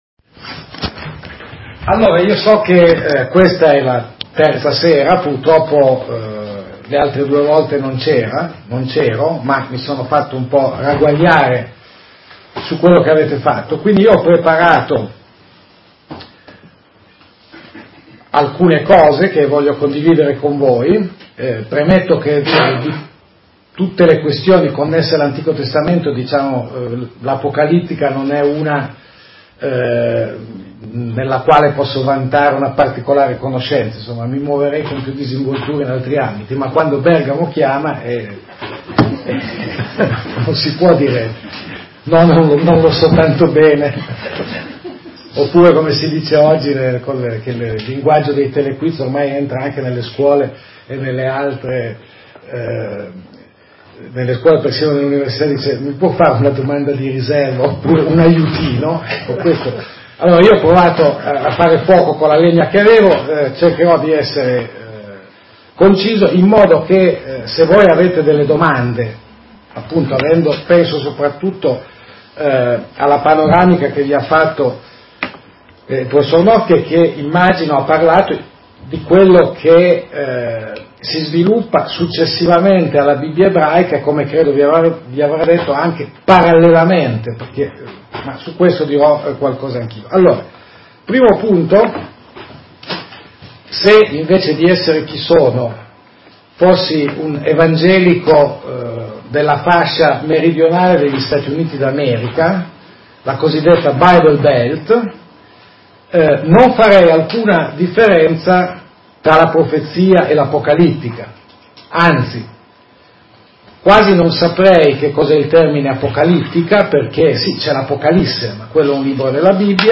In questa sezione si possono scaricare le registrazioni audio di alcune conferenze tenute presso il Centro Culturale Protestante.